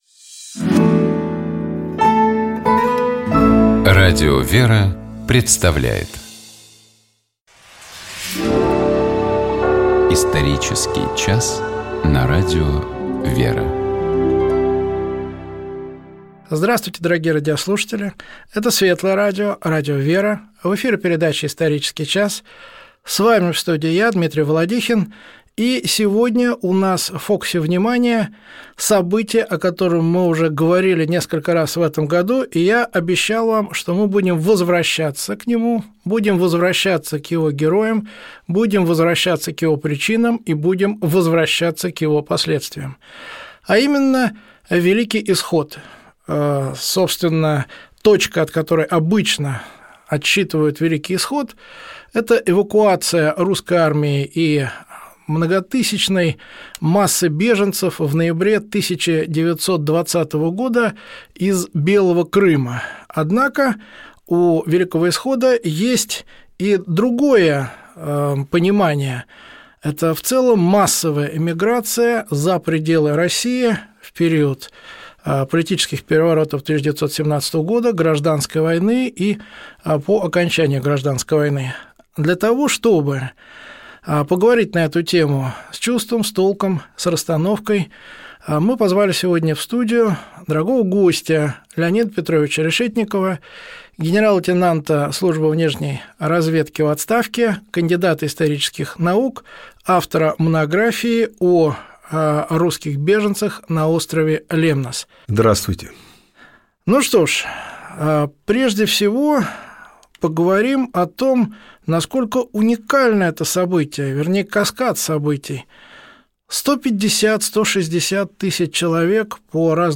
У нас в гостях был генерал-лейтенант Службы внешней разведки Российской Федерации в отставке, кандидат исторических наук, заведующий кафедрой истории и исторического архивоведения Московского государственного института культуры, специалист по истории русского зарубежья Леонид Решетников.